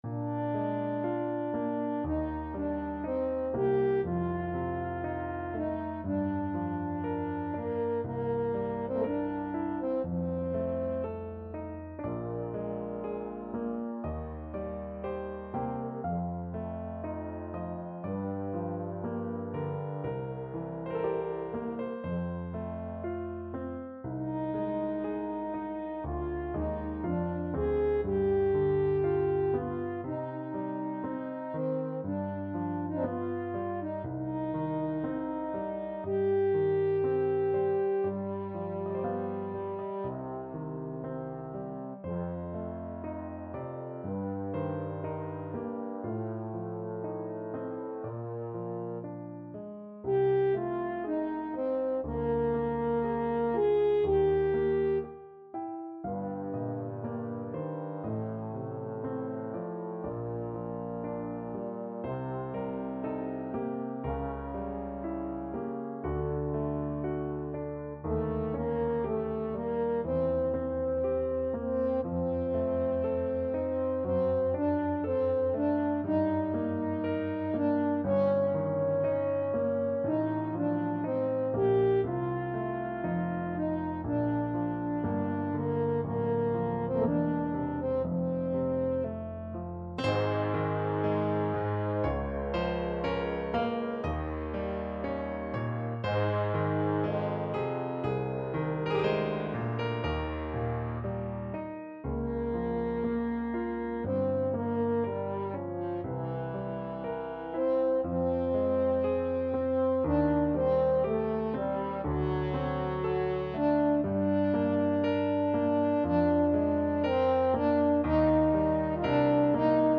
French Horn
Bb major (Sounding Pitch) F major (French Horn in F) (View more Bb major Music for French Horn )
~ = 60 Largo
C3-Ab5
3/2 (View more 3/2 Music)
Classical (View more Classical French Horn Music)